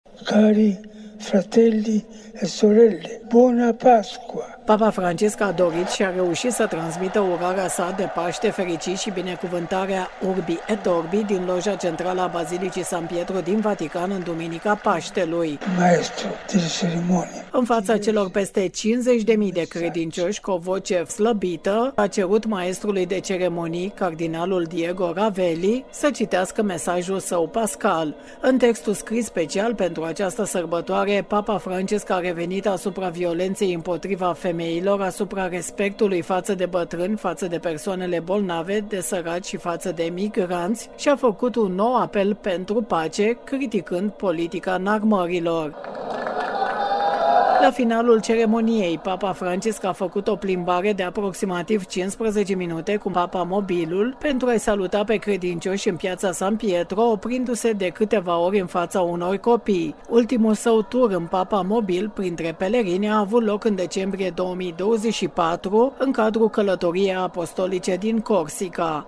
Ieri, în prima zi de Paște, Papa Francisc a ieşit în balconul Bisericii Sfântul Petru de la Vatican la sfârşitul Liturghiei, pentru a oferi binecuvântarea tradiţională Urbi et Orbi. O corespondență